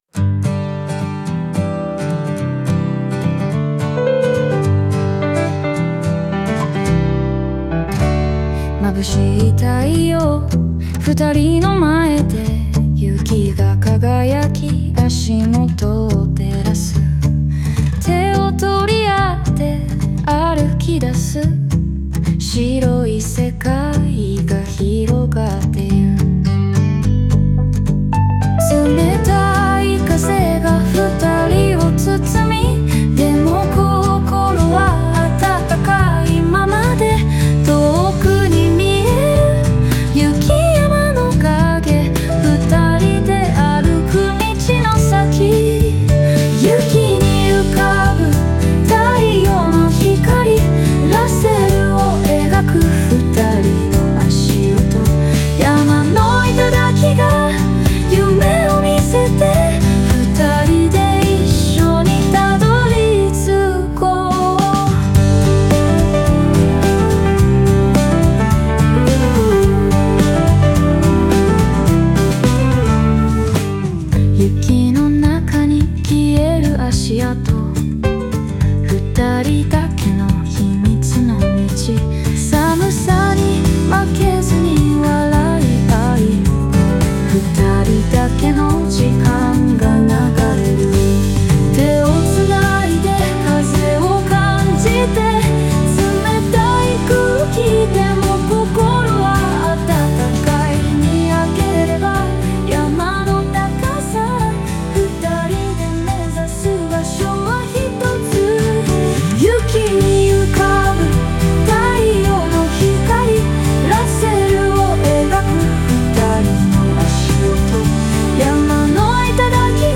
オリジナル曲♪